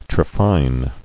[tre·phine]